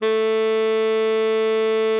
Added more instrument wavs
bari_sax_057.wav